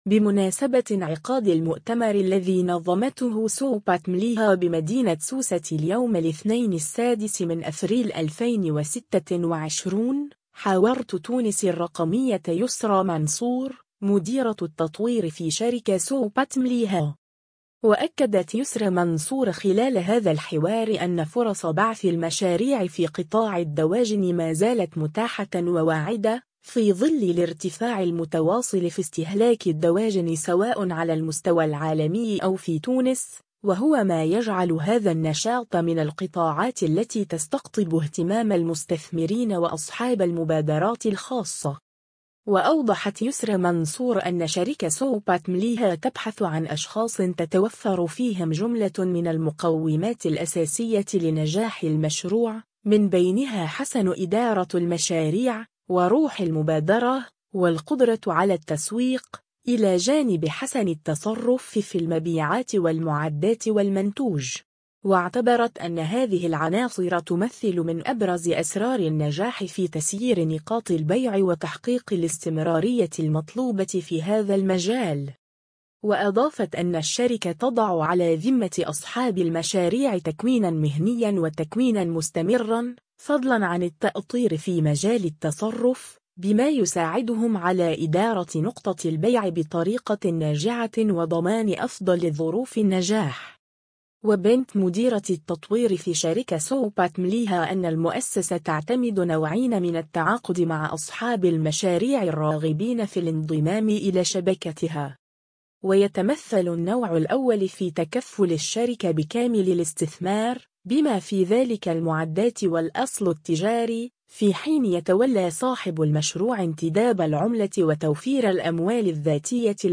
بمناسبة انعقاد المؤتمر الذي نظمته SOPAT-MLIHA بمدينة سوسة اليوم الاثنين 6 أفريل 2026،